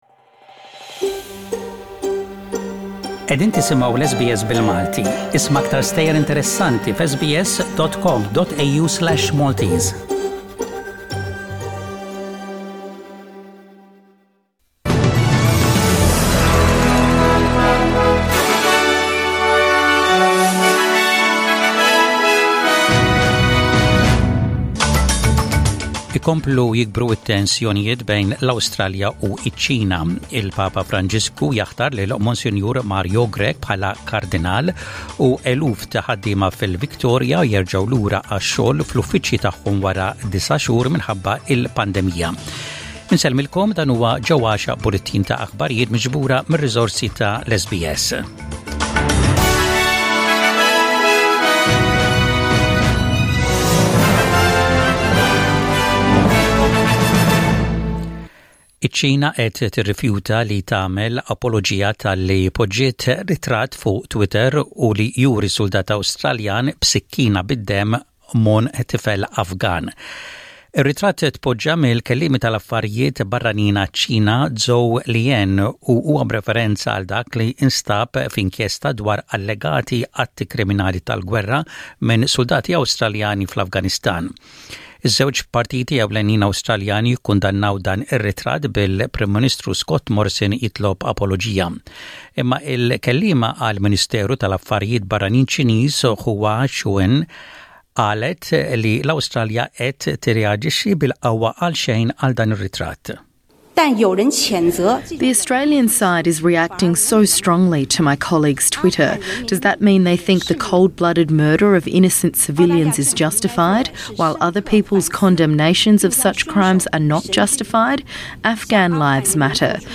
SBS Radio | Aħbarijiet bil-Malti: 01/12/20